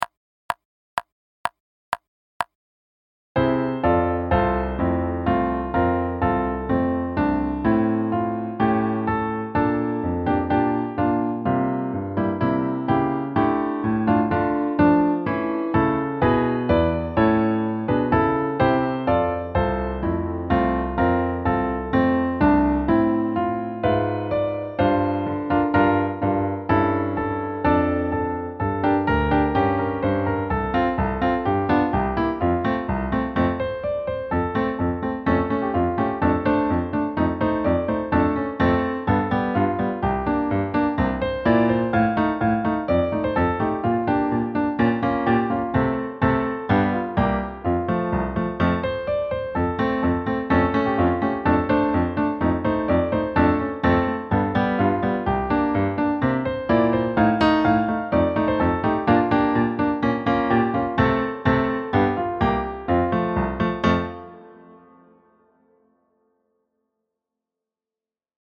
Accompaniment